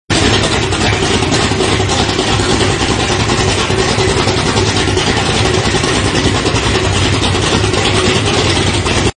Starting Boat Sound Effect Free Download
Starting Boat